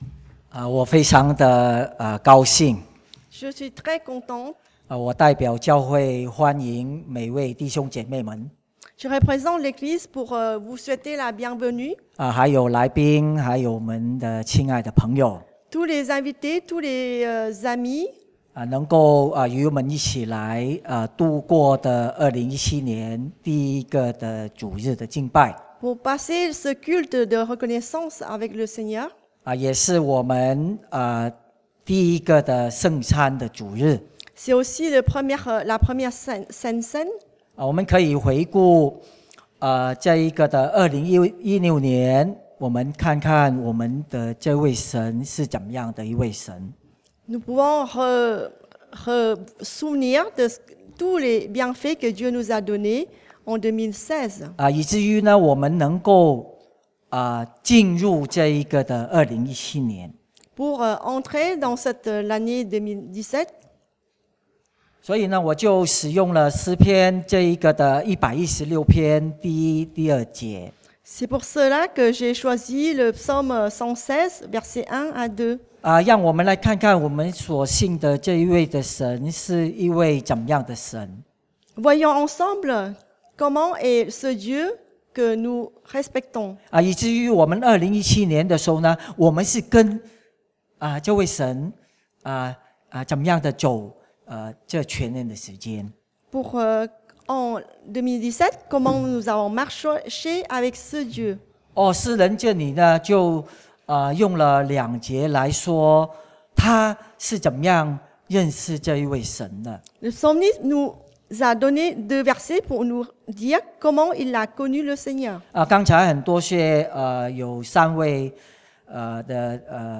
(chinois traduit en français)
Prédication